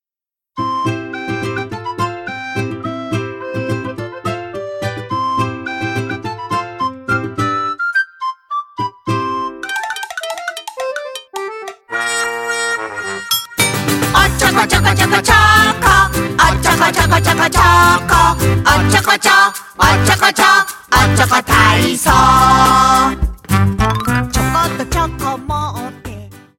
♬ダンス・体操♬